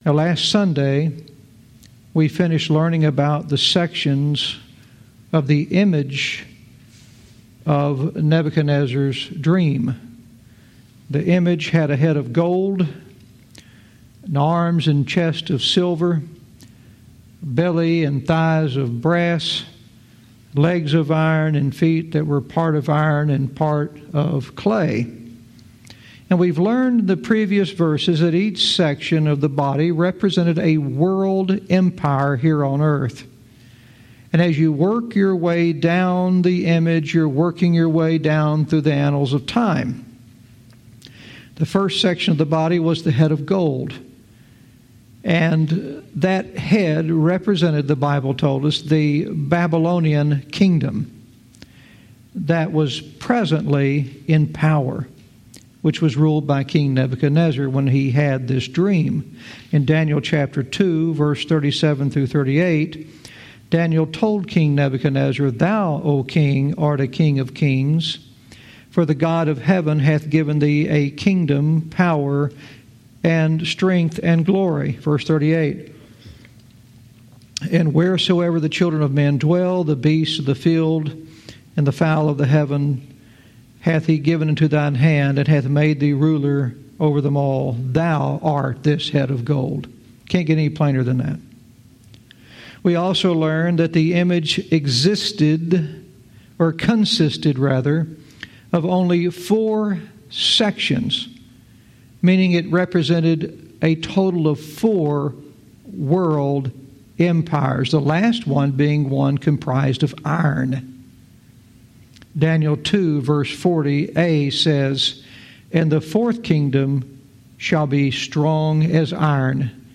Verse by verse teaching - Daniel 2:44 "A Kingdom Set Up"